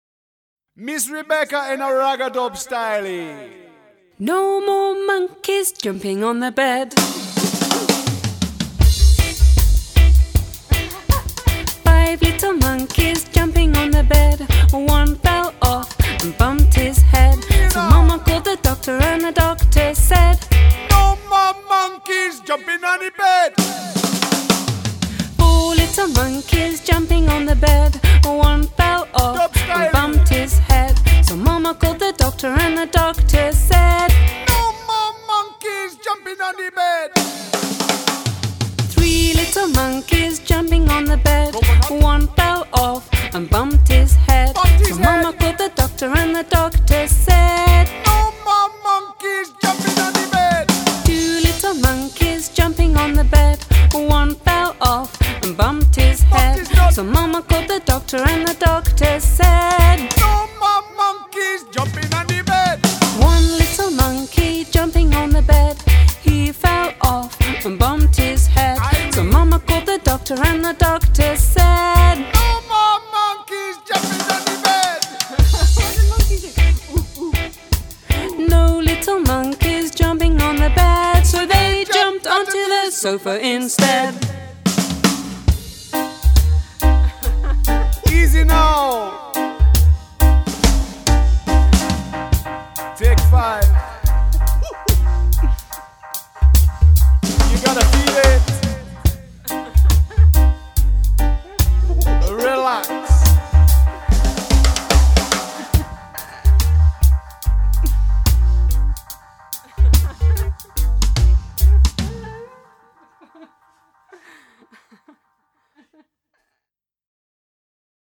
Counting SongsFun & Punk